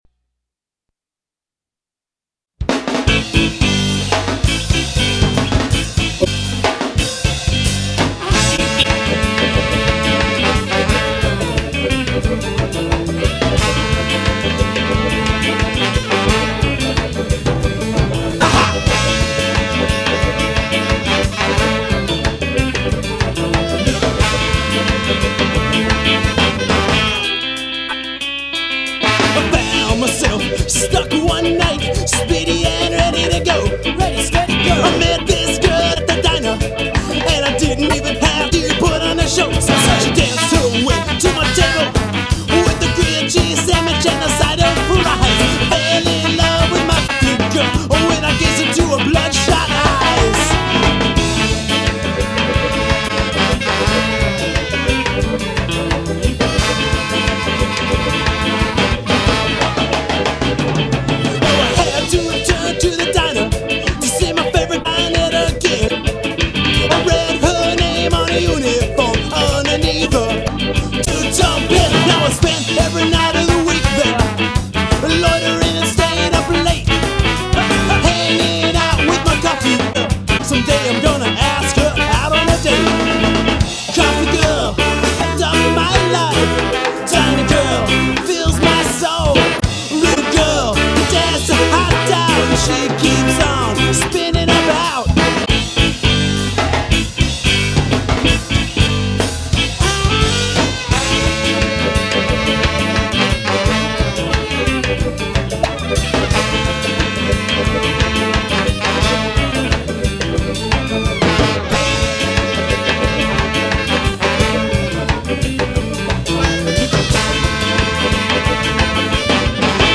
Recorded live at 70Hurtz studio in Argyle, TX 1996